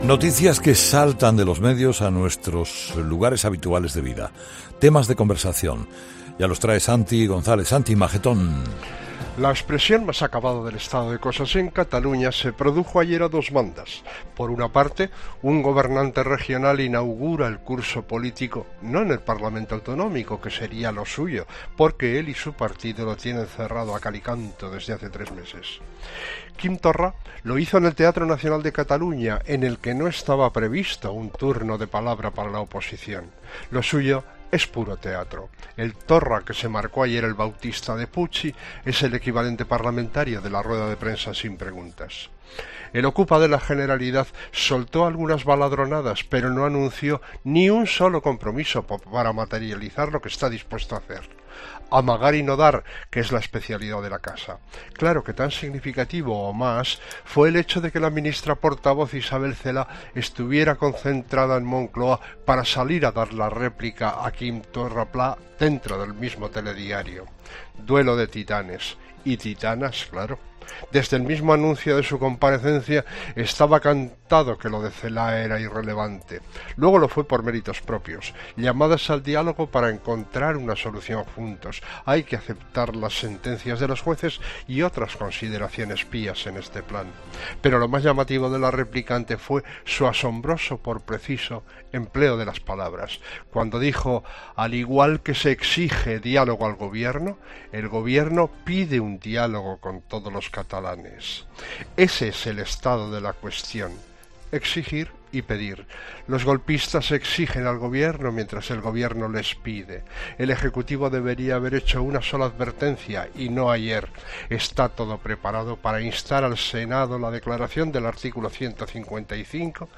El comentario de Santiago González